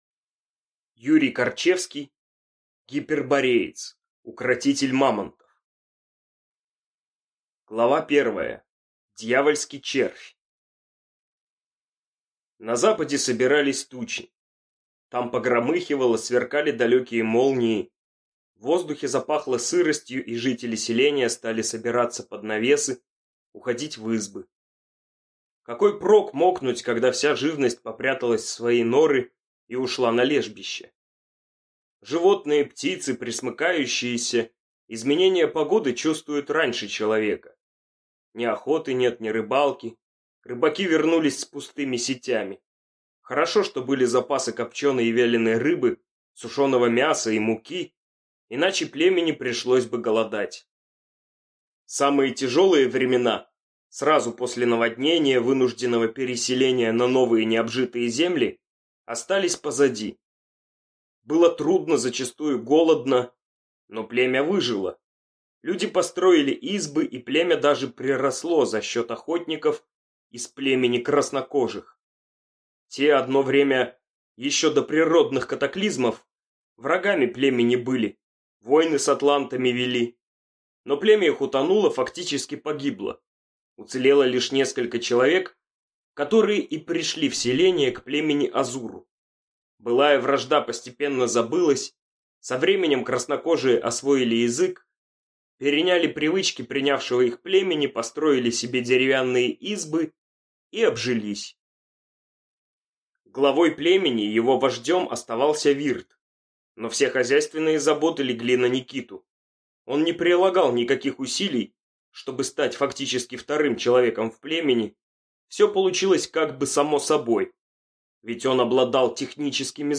Аудиокнига Гипербореец. Укротитель мамонтов | Библиотека аудиокниг